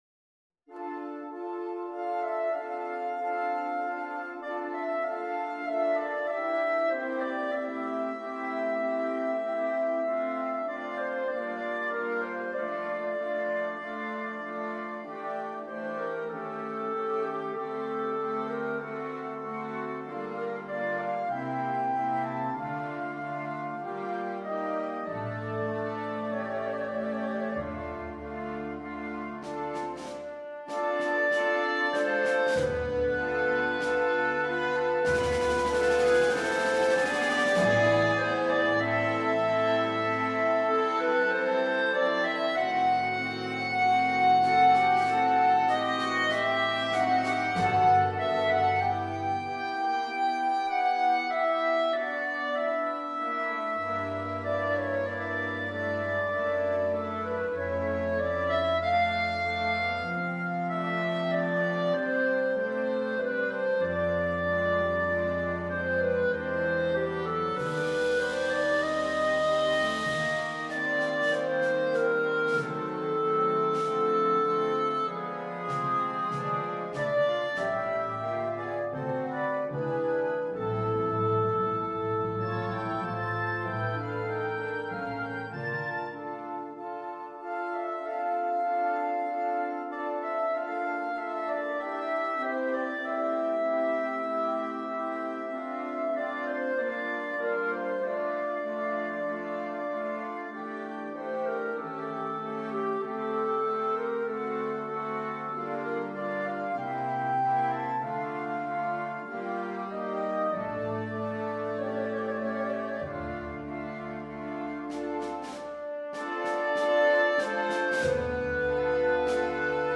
4/4 (View more 4/4 Music)
= 48 Lentamente. Molto cantabile